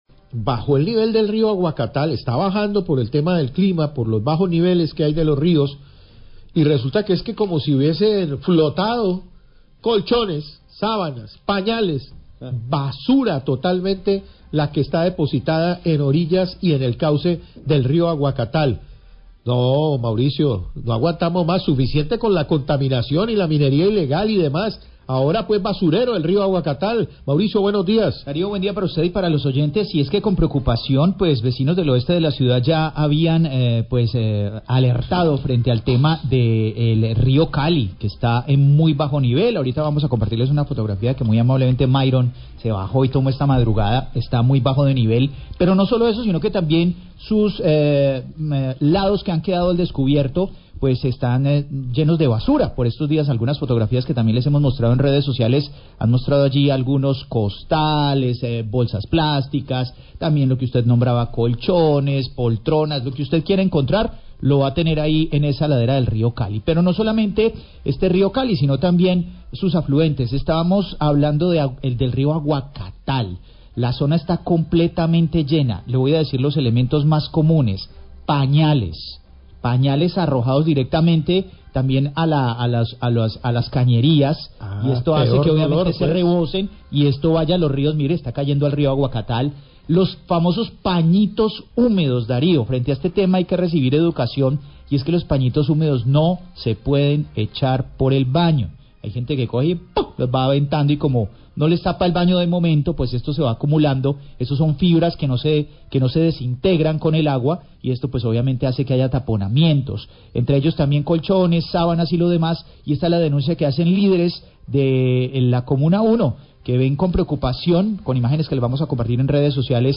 Radio
Periodistas comentan sobre la reducción del caudal del Río Aguacatal que reveló contaminación por residuos ordinarios que se desechan desde las viviendas del sector como colchones, muebles, bolsas con basuras, entre otrops desechos. Piden a autoridades que tomen cartras en el asunto para proteger el río.